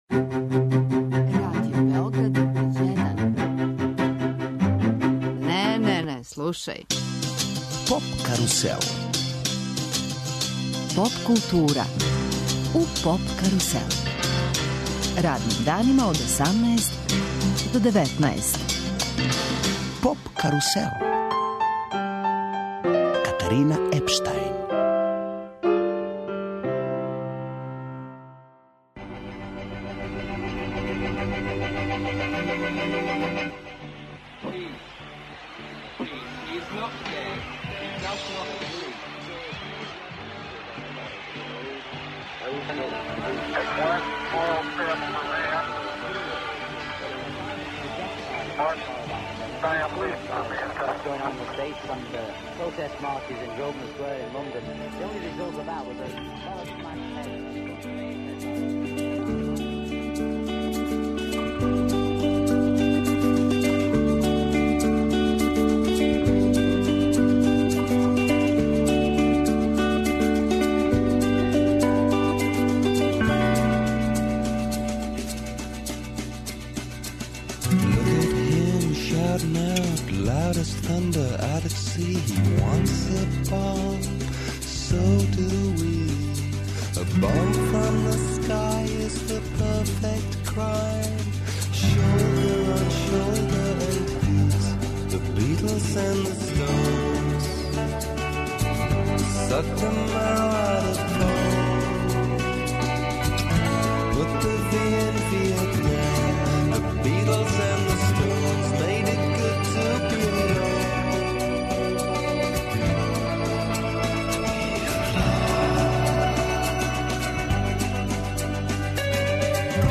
Гост емисије је градоначелник Пуле Борис Милетић.